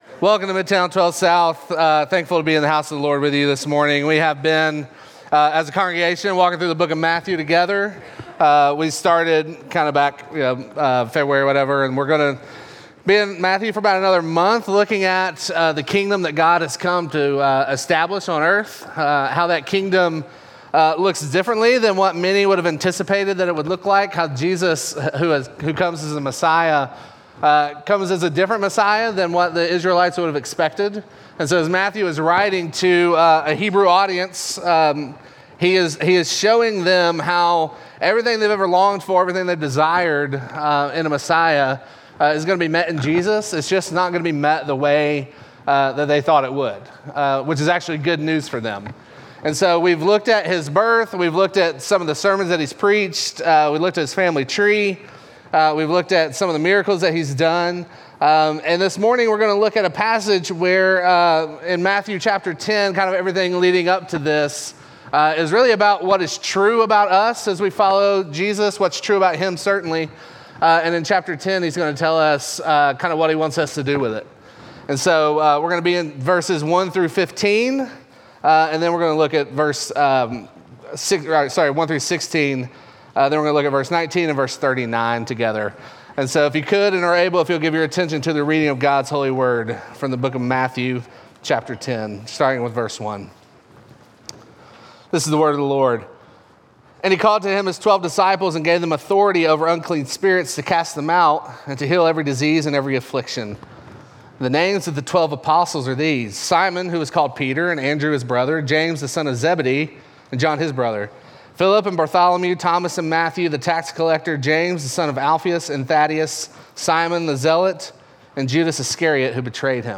Midtown Fellowship 12 South Sermons Going On A Sheep Hunt Apr 14 2024 | 00:36:27 1x 00:00 / 00:36:27 Subscribe Share Apple Podcasts Spotify Overcast RSS Feed Share Link Embed